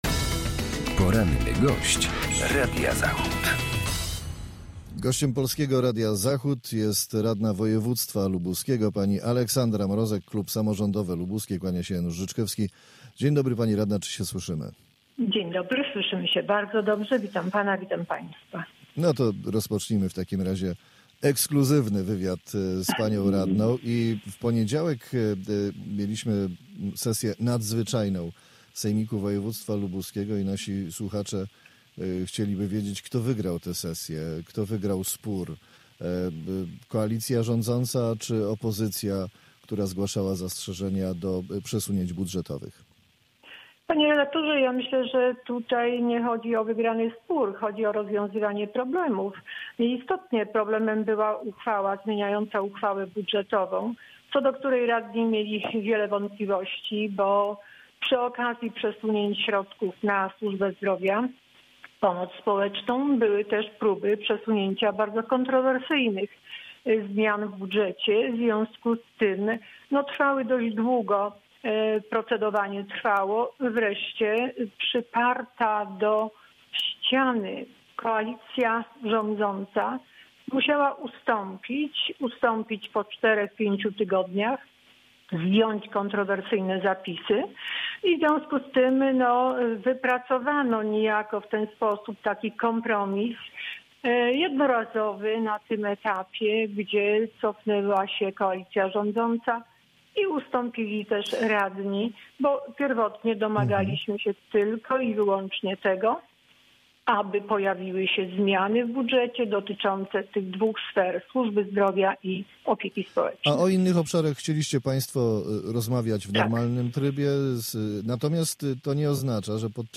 Z radną lubuskiego sejmiku z klubu Samorządowe Lubuskie rozmawia